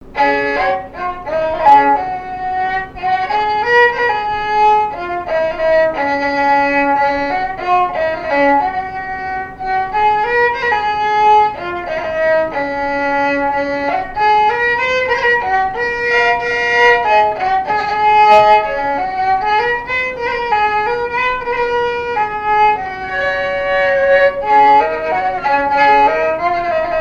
Répertoire de marches de noce et de danse
Pièce musicale inédite